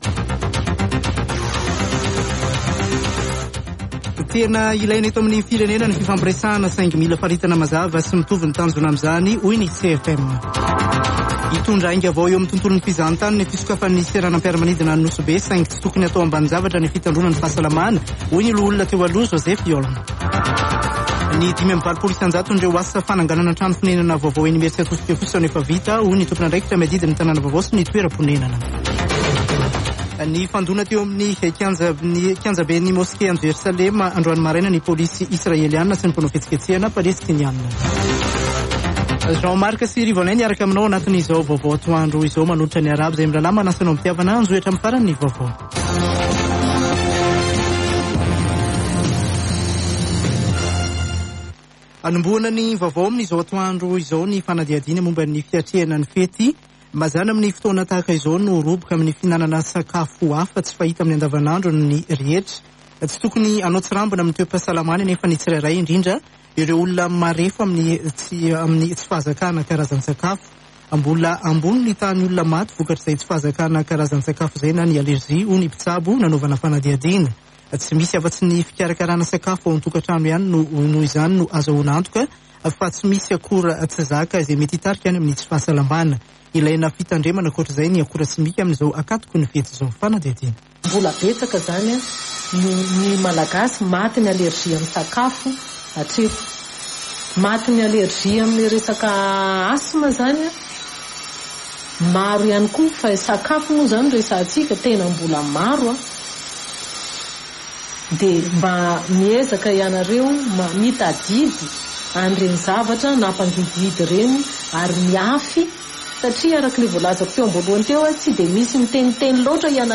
[Vaovao antoandro] Zoma 15 aprily 2022